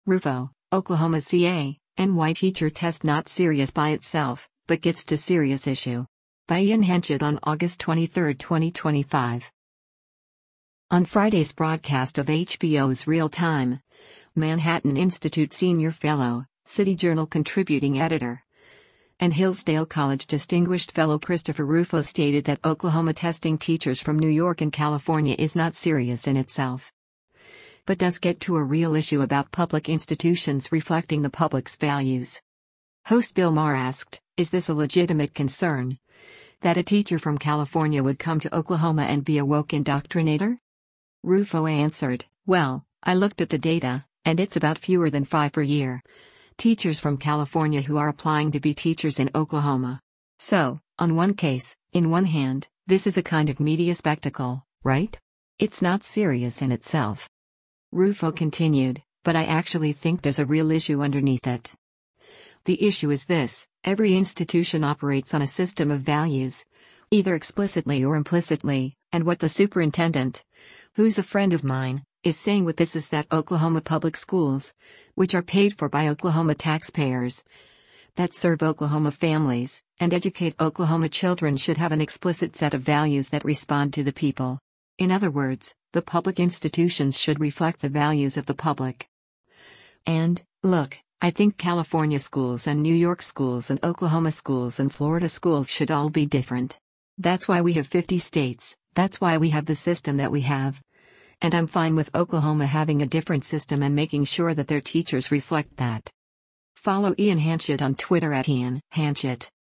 On Friday’s broadcast of HBO’s “Real Time,” Manhattan Institute Senior Fellow, City Journal Contributing Editor, and Hillsdale College Distinguished Fellow Christopher Rufo stated that Oklahoma testing teachers from New York and California is “not serious in itself.” But does get to a “real issue” about public institutions reflecting the public’s values.
Host Bill Maher asked, “Is this a legitimate concern, that a teacher from California would come to Oklahoma and be a woke indoctrinator?”